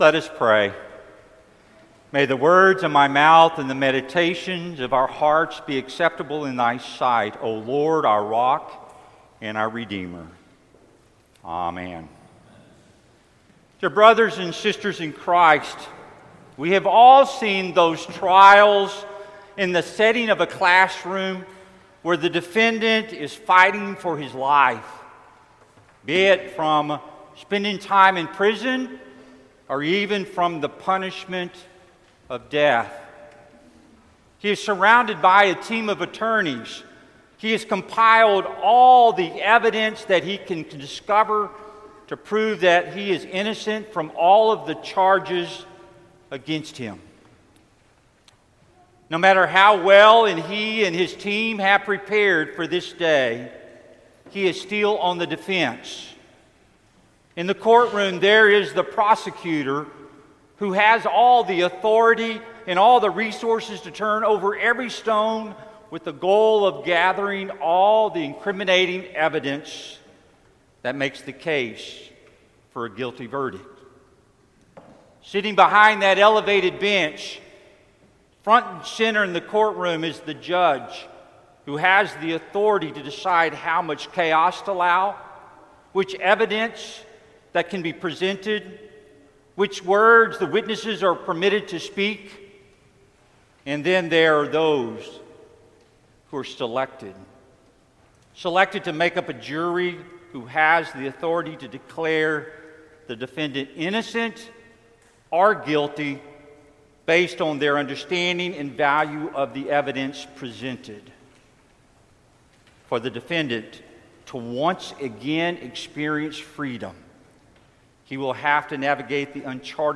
Sermon for Lent Midweek 3